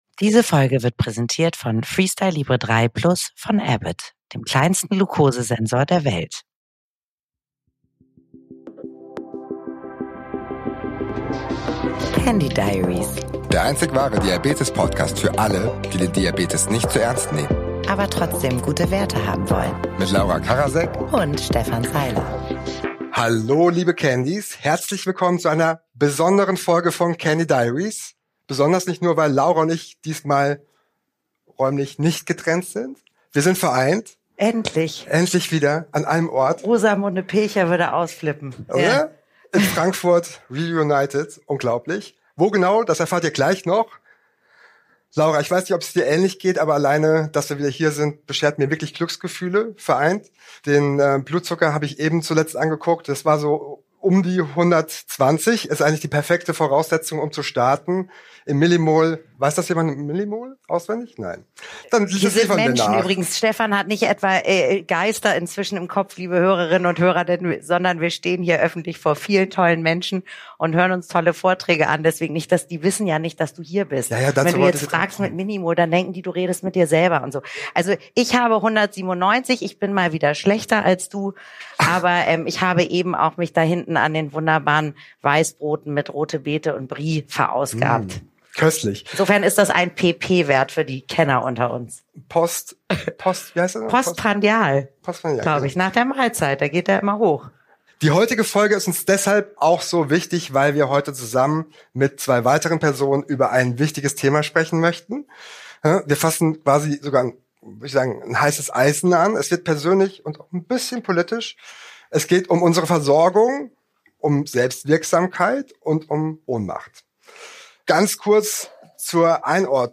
Live-Special
Laut, klar, live – warum Diabetes politisch unsichtbar bleibt und was wir dagegen tun können.